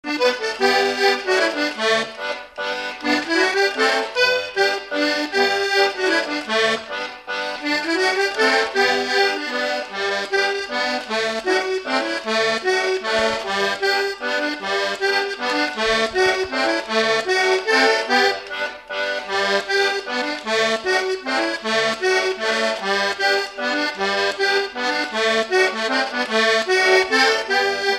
Mazurka
Instrumental
danse : mazurka
Pièce musicale inédite